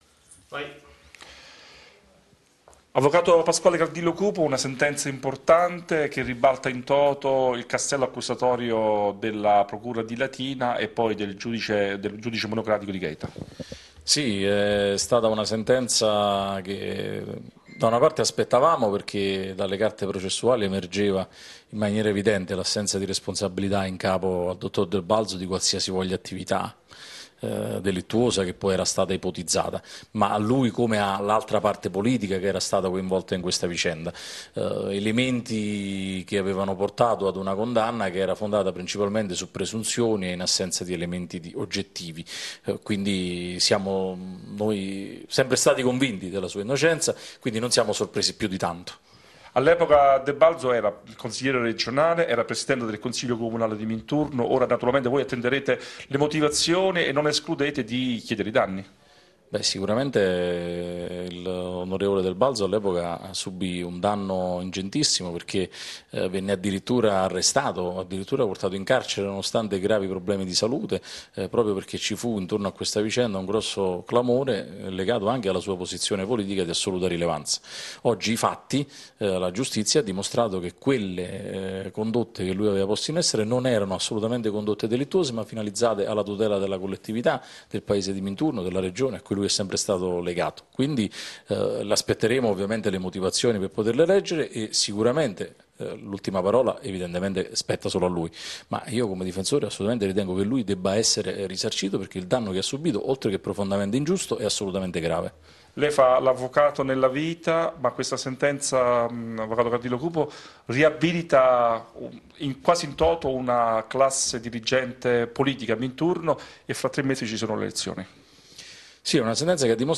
Intervista all’Avv.